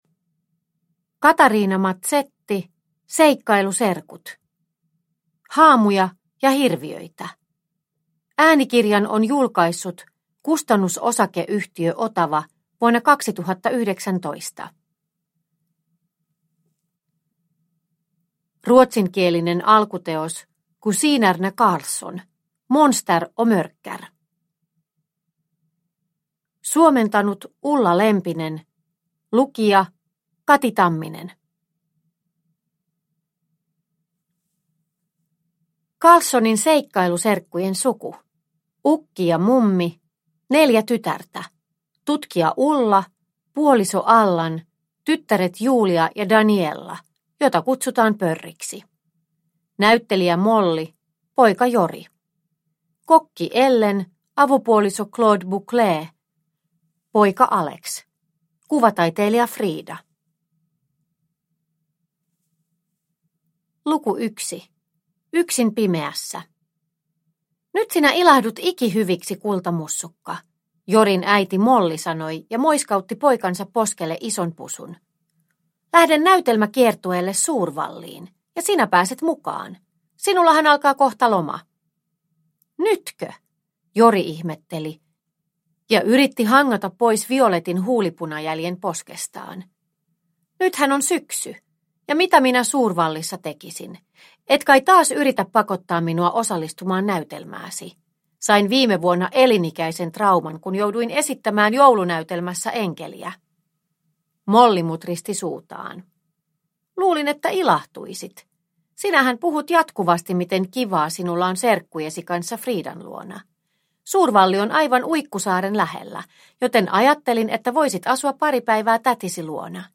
Haamuja ja hirviöitä – Ljudbok – Laddas ner